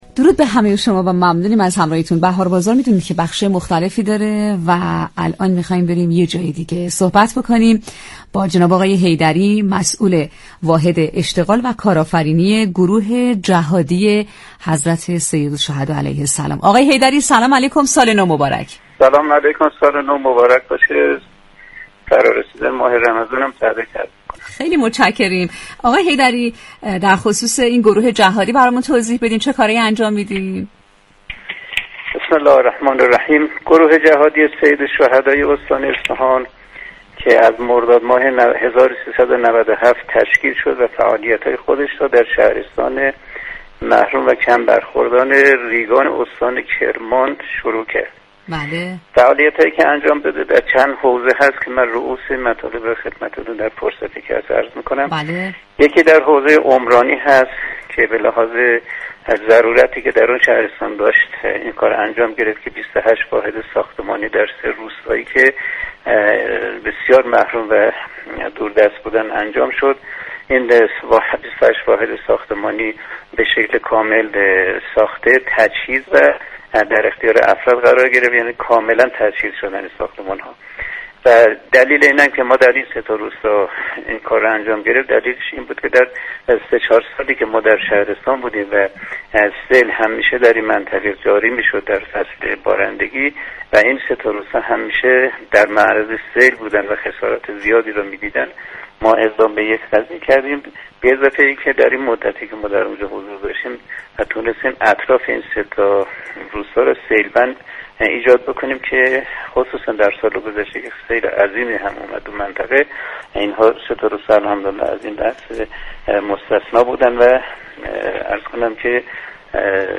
گفتگوی رادیویی؛